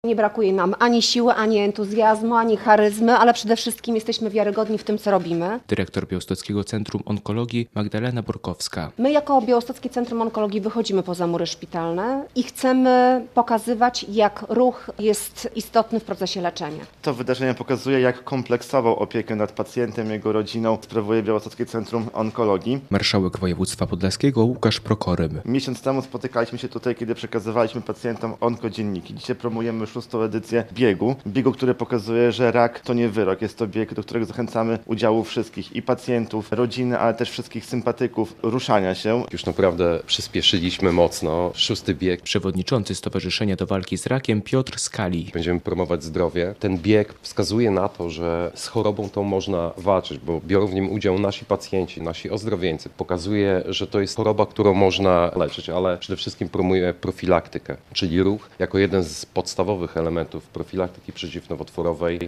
Konferencja prasowa przed szóstą edycją biegu OnkoRun, 28.06.2024, fot.
relacja
Do udziału w szóstej edycji OnkoRun zachęcali organizatorzy wydarzenia.